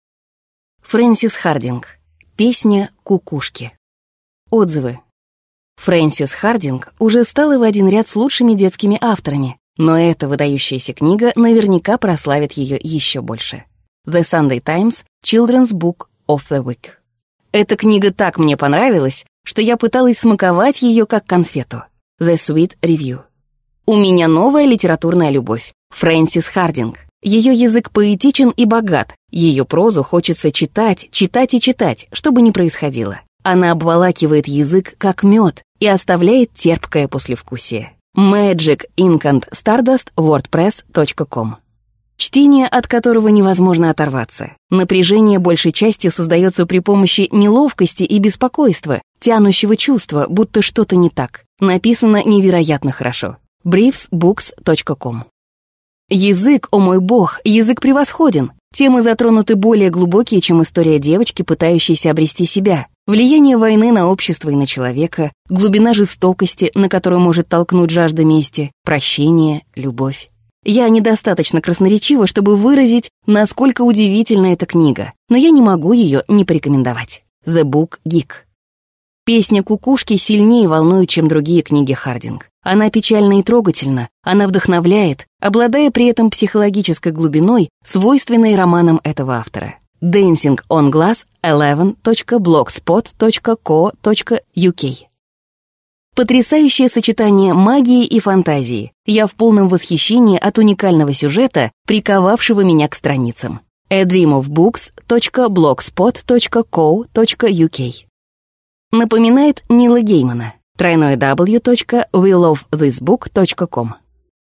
Аудиокнига Песня кукушки | Библиотека аудиокниг
Aудиокнига Песня кукушки Автор Фрэнсис Хардинг